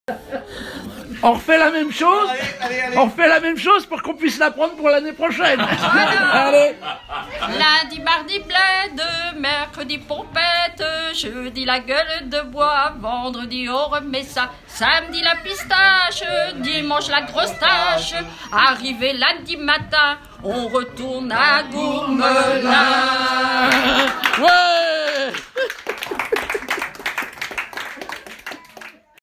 Témoignages et chants brefs
Pièce musicale inédite